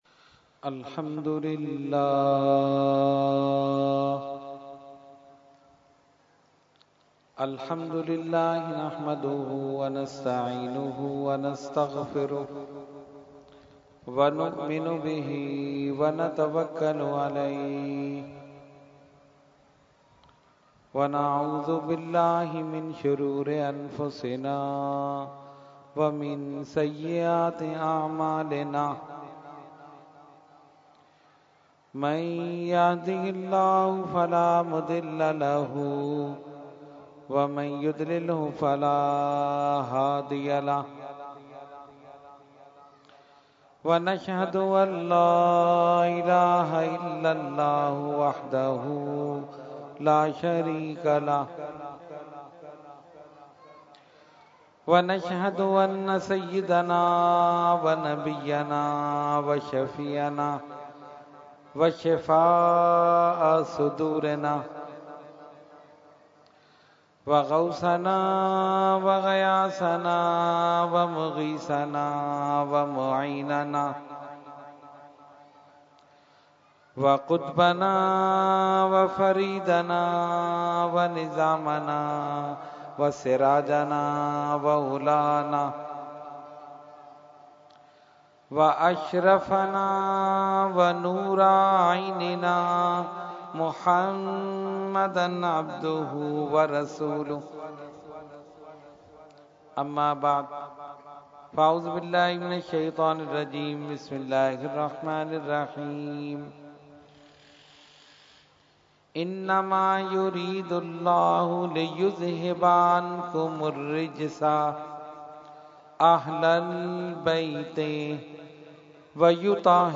Category : Speech | Language : UrduEvent : Muharram 2016